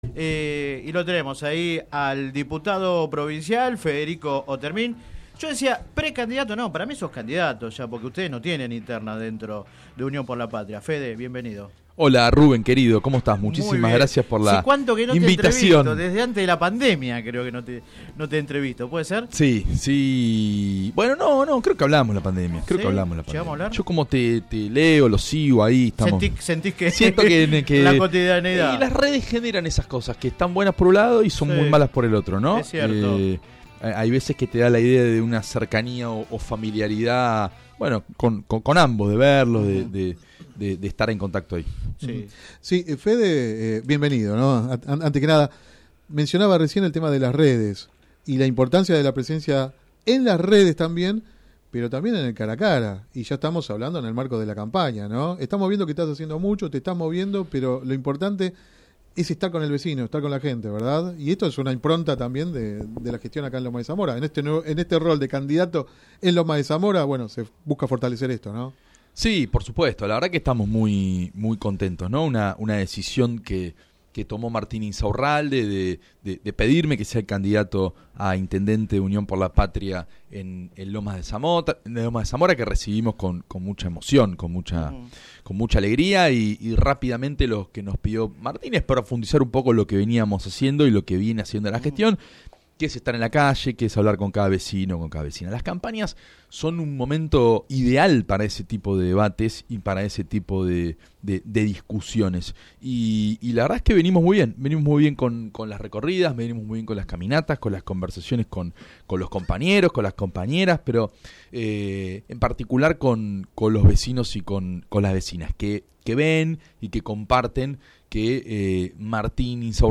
El precandidato a intendente lomense de “Unión por la Patria” brindó una extensa entrevista en el programa radial Sin Retorno.